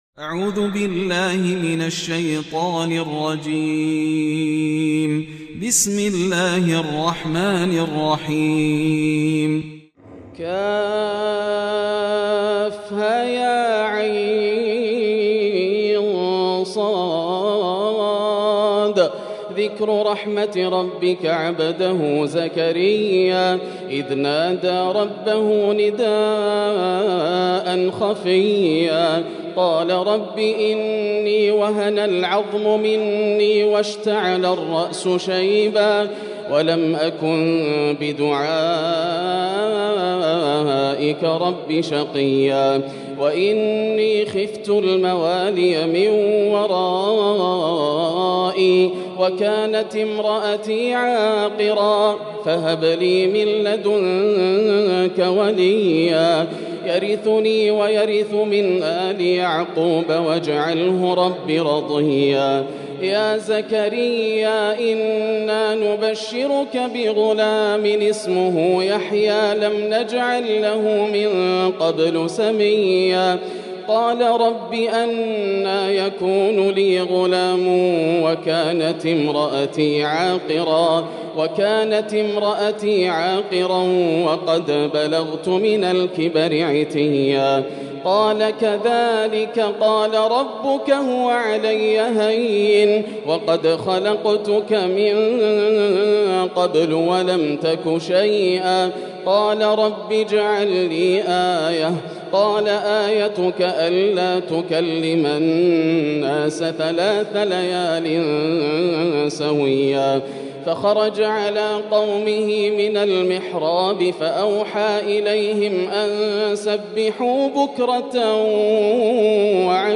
سورة مريم من تهجد رمضان 1441هـ > السور المكتملة > رمضان 1441هـ > التراويح - تلاوات ياسر الدوسري